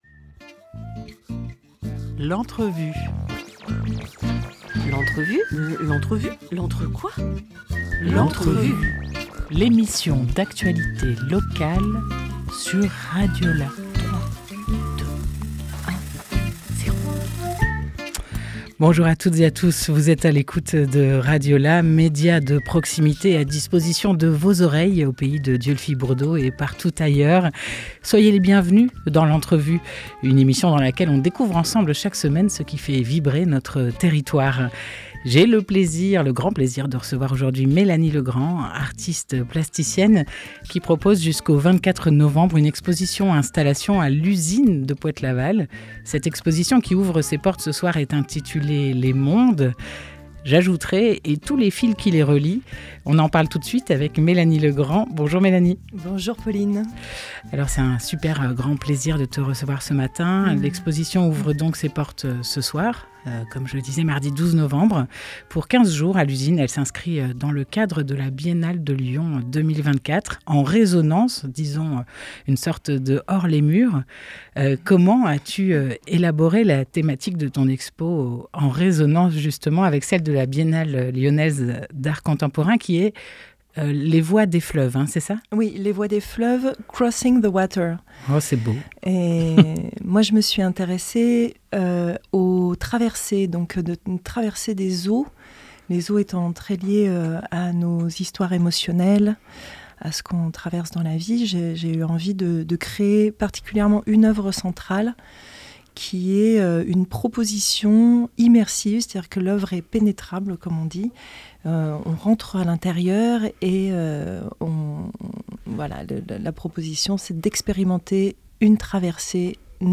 12 novembre 2024 12:29 | Interview